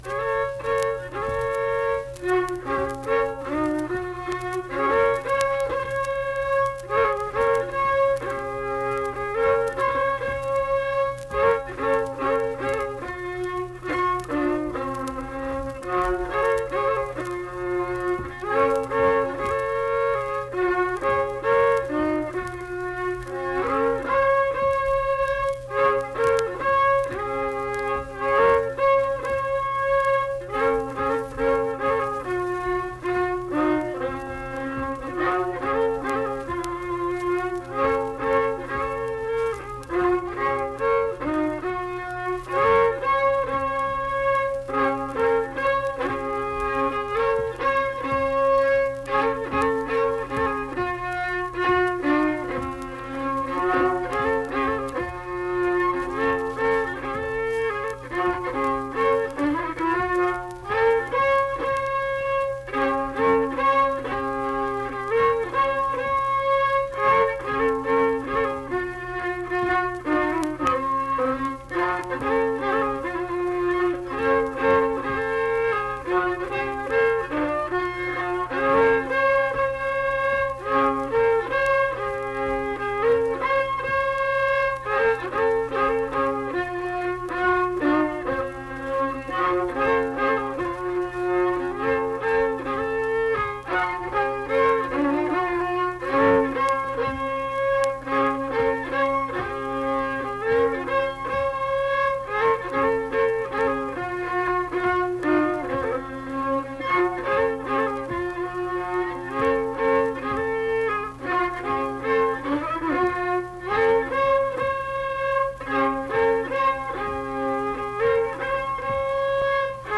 Unaccompanied fiddle and vocal music performance
Instrumental Music
Fiddle